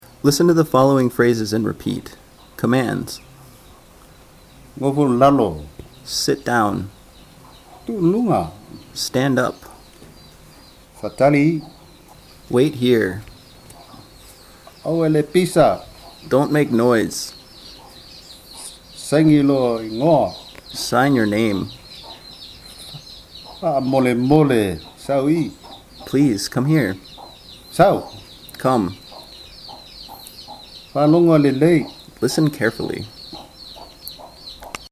lesson 01 - the samoan alphabet - 2 - phrase practice.mp3